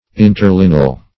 Interlineal \In`ter*lin"e*al\, Interlinear \In`ter*lin"e*ar\, a.